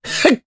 Mitra-Vox_Jump_jp.wav